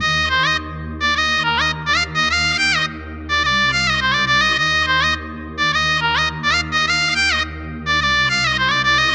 Gully-Loops-Jadoo-Synth-Loop-BPM-105.wav